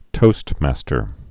(tōstmăstər)